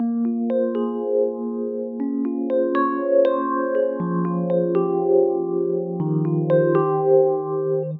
描述：经典的Wurlitzer 电子钢琴。
Tag: 120 bpm Chill Out Loops Piano Loops 1.35 MB wav Key : Unknown